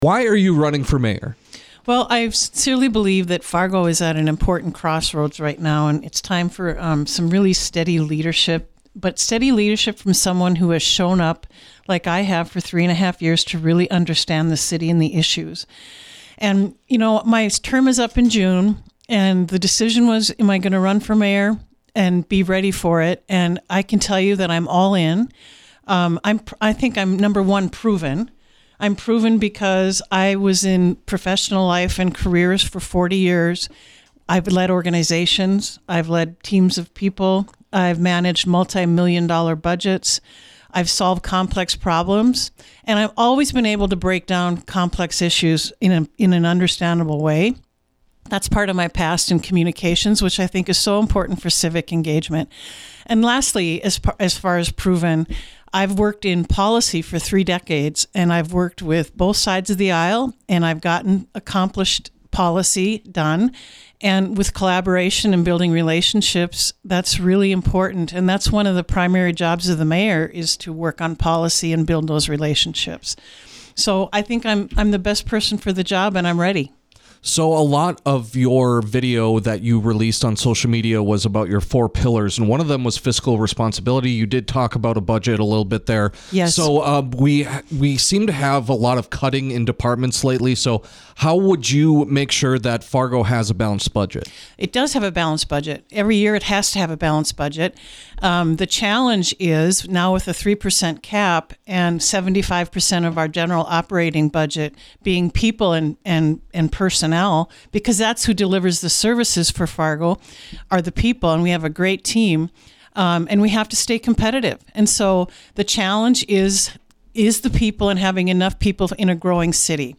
kolpack-mayor-interview.mp3